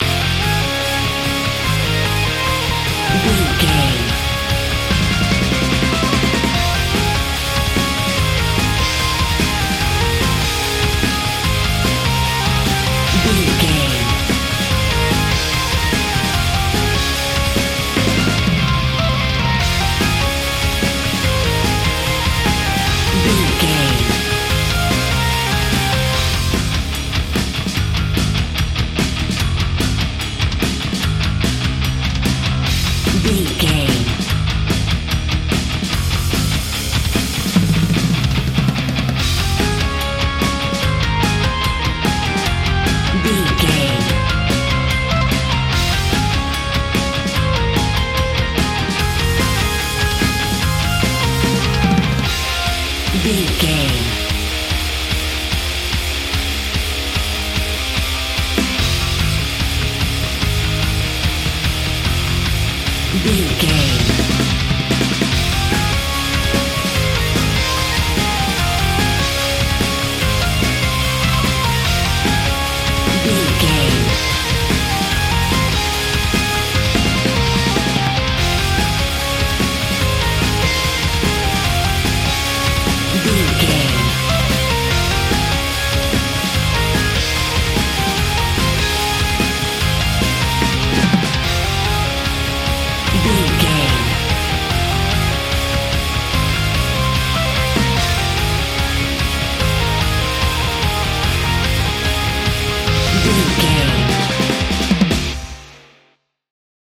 Epic / Action
Fast paced
In-crescendo
Aeolian/Minor
Fast
haunting
chaotic